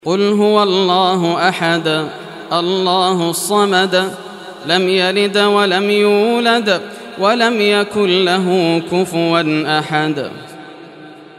Surah Al-Ikhlas Recitation by Yasser al Dosari
Surah Al-Ikhlas, listen or play online mp3 tilawat / recitation in Arabic in the beautiful voice of Sheikh Yasser al Dosari.